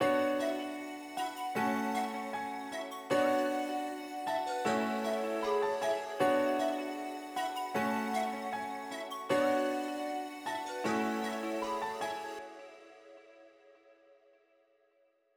Ziggy 155 bpm.wav